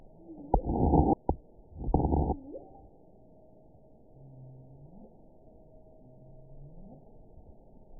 event 919291 date 12/29/23 time 19:10:08 GMT (1 year, 4 months ago) score 7.55 location TSS-AB04 detected by nrw target species NRW annotations +NRW Spectrogram: Frequency (kHz) vs. Time (s) audio not available .wav